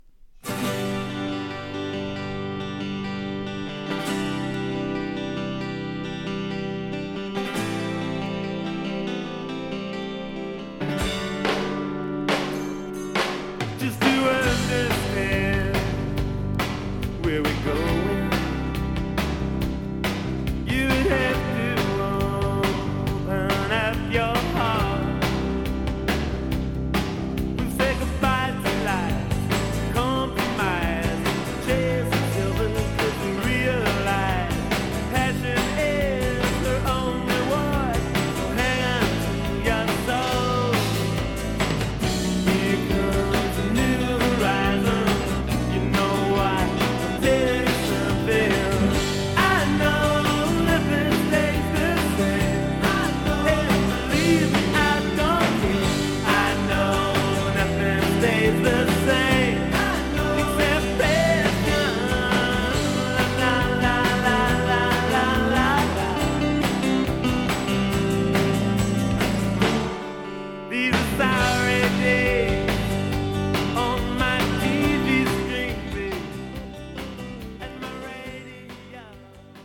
青春迸る究極のネオアコ
簡素なパーカッションとアコギが織りなす